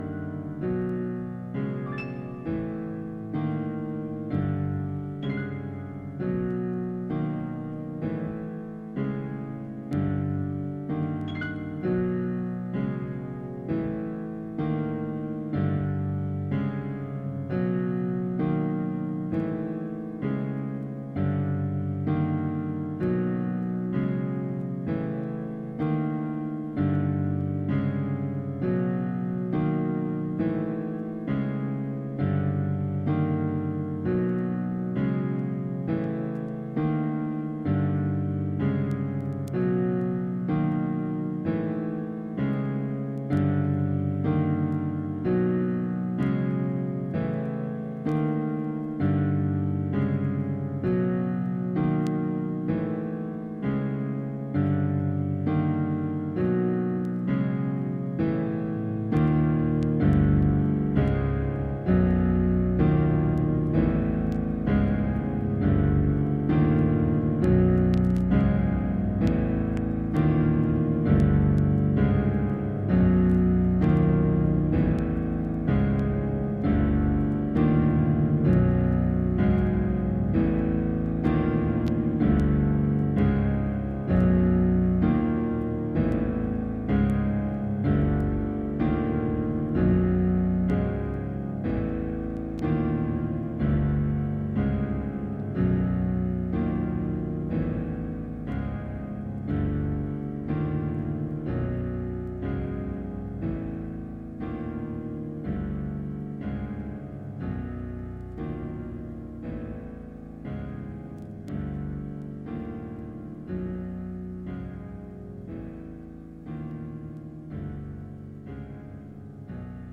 six piano pieces
a crepuscular suite